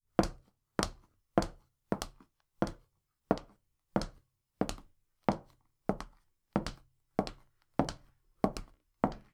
Womens_shoes_2.wav